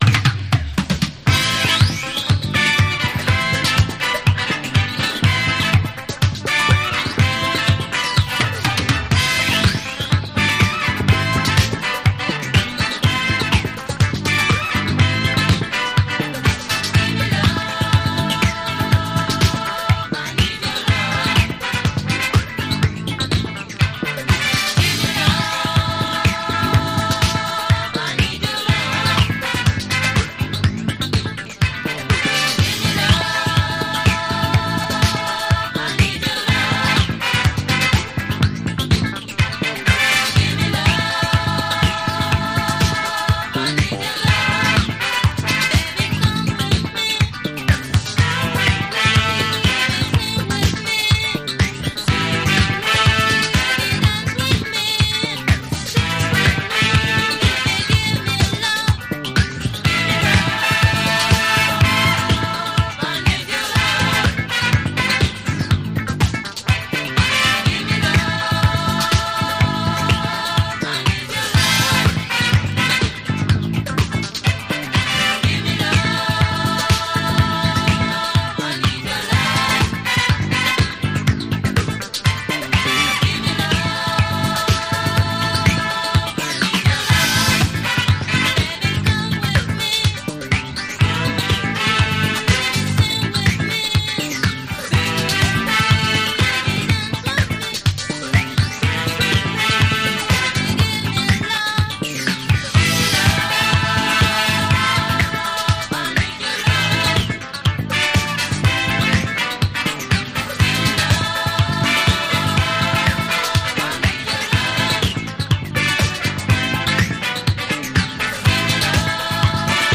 recorded in the late 70s in Paris (France)
Lead Guitar
Rhythm Guitar
Backing Vocals
Bass Guitar
Drums